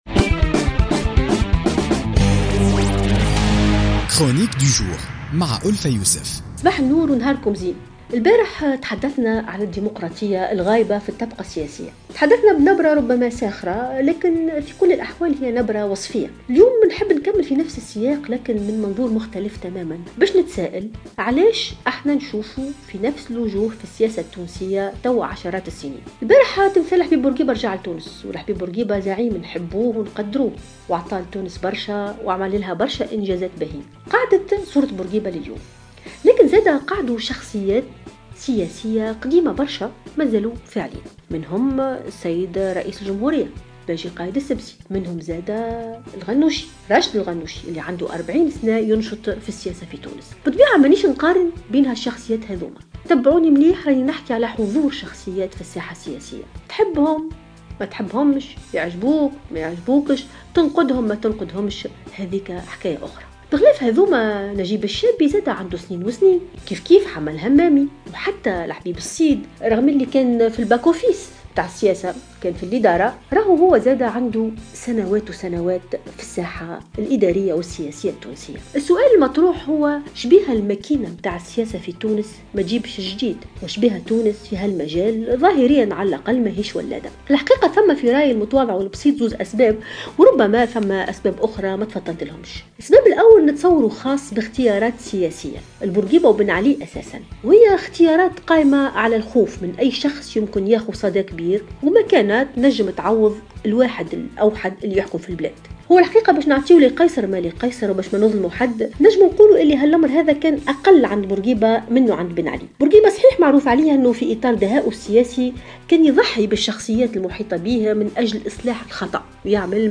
تحدثت الأستاذة الجامعية ألفة يوسف في افتتاحية اليوم الأربعاء 25 ماي 2016 عن افتقار الساحة السياسية في تونس لوجوه جديدة.